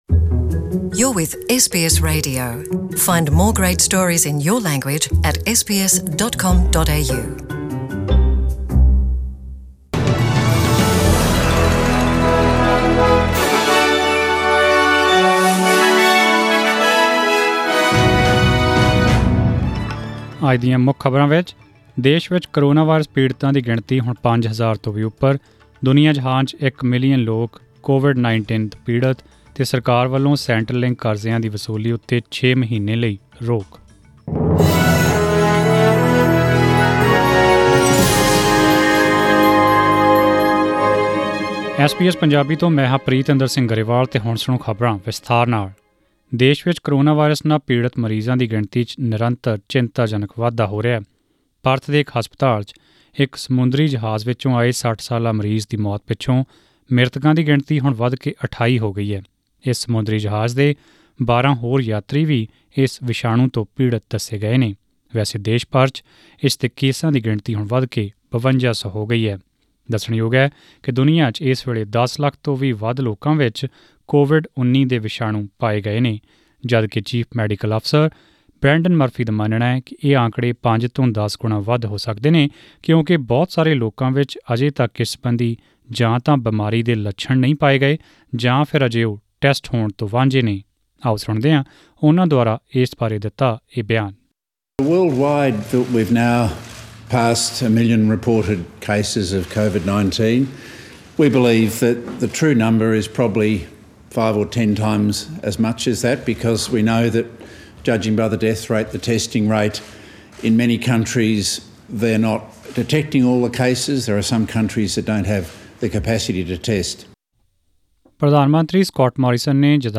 Australian News in Punjabi: 3 April 2020